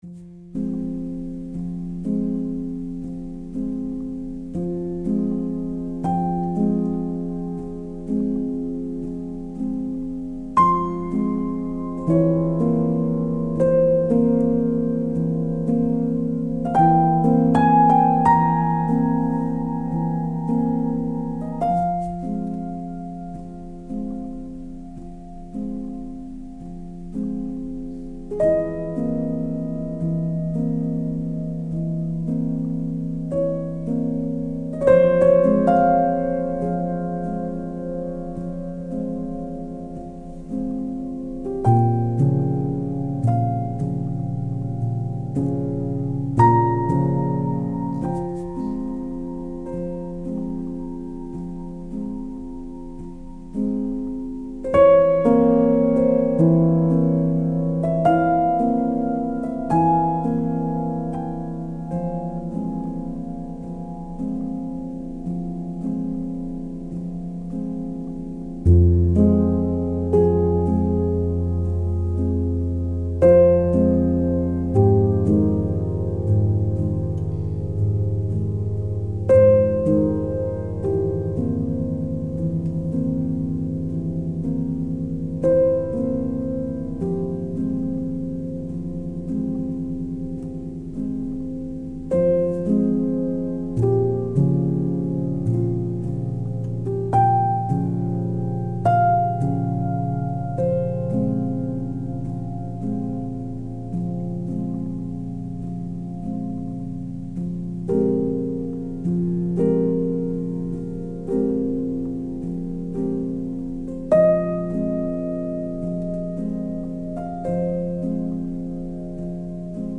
a modern classical / classical crossover solo piano single